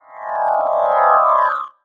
magic_device_transform_03.wav